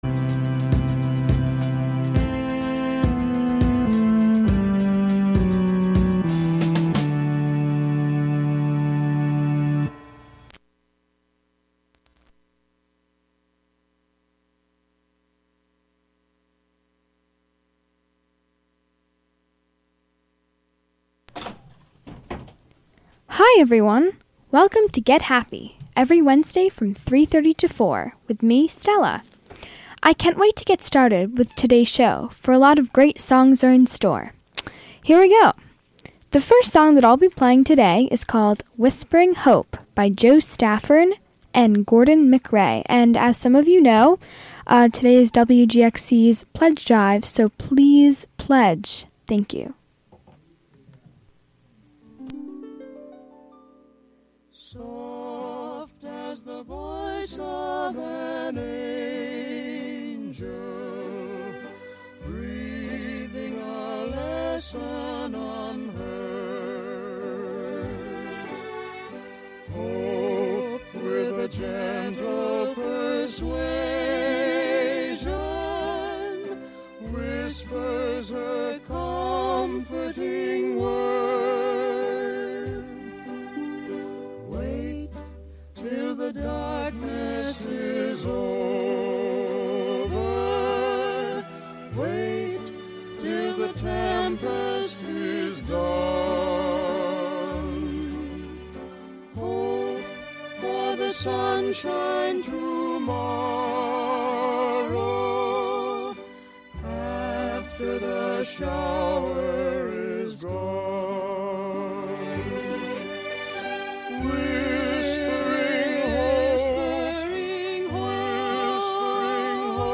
a half hour of 1930s and 1940s music, with an occasional foray into other genres.